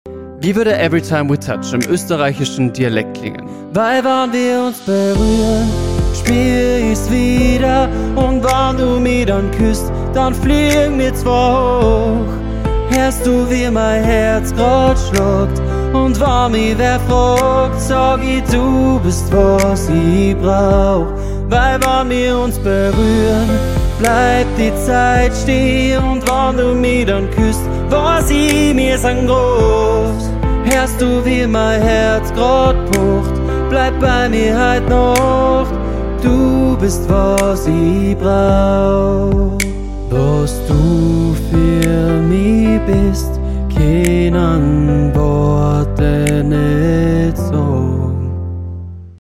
im österreichischen Dialekt
Austro-Pop